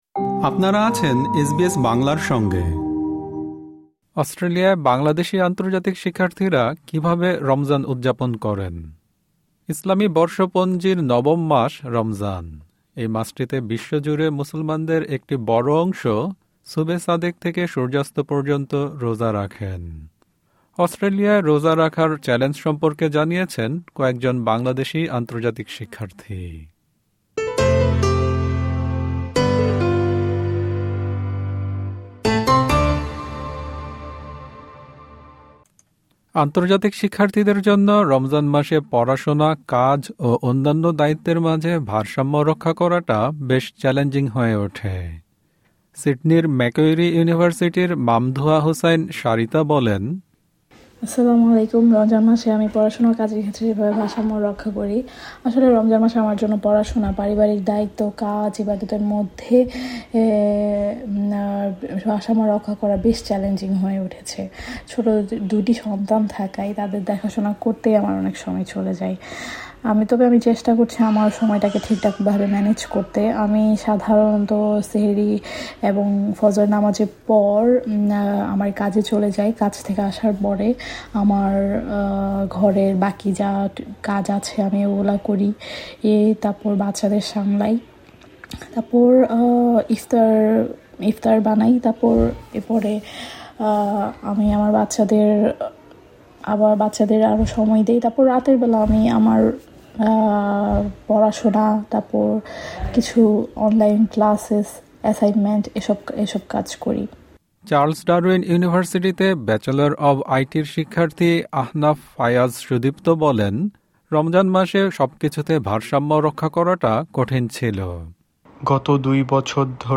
এই মাসটিতে বিশ্বজুড়ে মুসলমানদের একটি বড় অংশ সুবেহ সাদেক থেকে সূর্যাস্ত পর্যন্ত রোজা রাখেন। অস্ট্রেলিয়ায় রোজা রাখার চ্যালেঞ্জ সম্পর্কে জানিয়েছেন কয়েকজন বাংলাদেশী আন্তর্জাতিক শিক্ষার্থী।